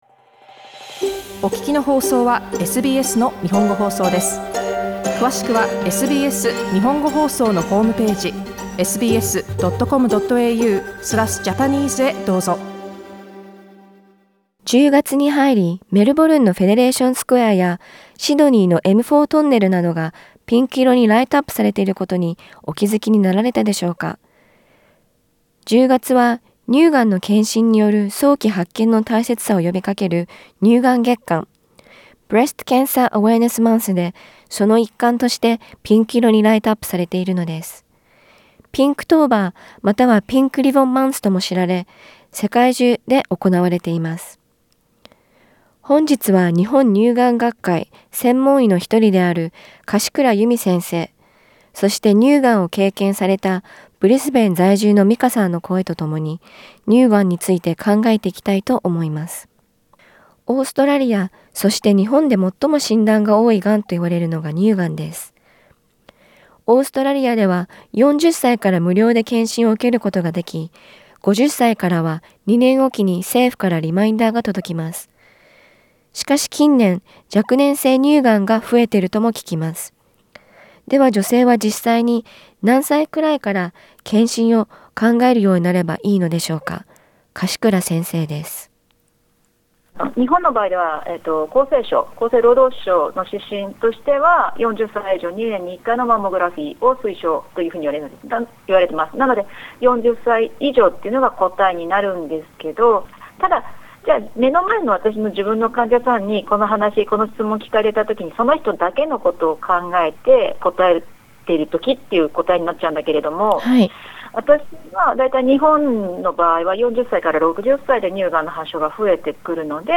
普段なかなか会話することのない乳がんについて、この機会に話してみませんか？ 日本乳癌学会専門医をお招きし、聞いてみました。